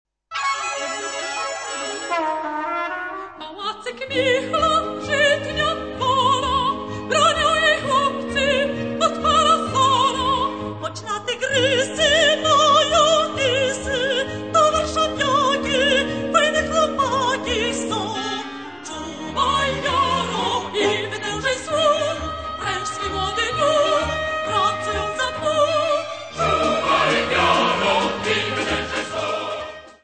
24 Polish Scout songs.